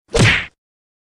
punch-sound-effect-meme